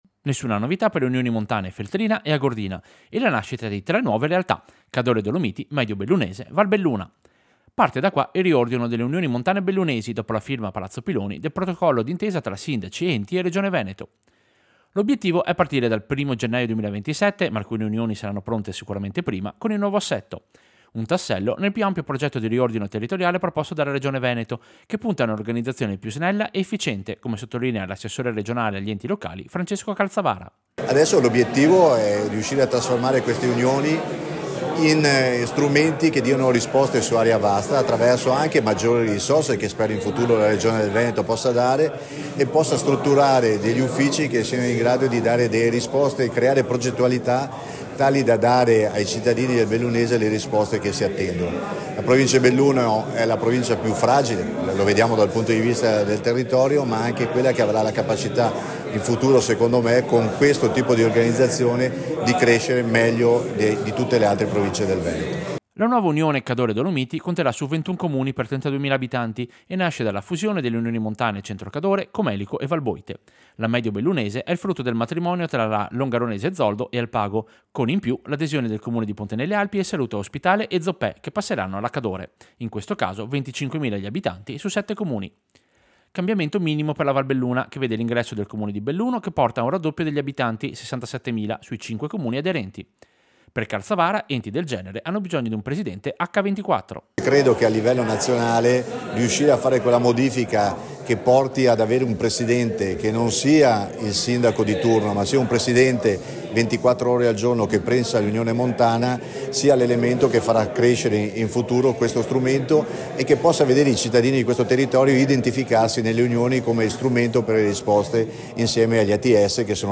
Servizio-Riordino-Unioni-Montane.mp3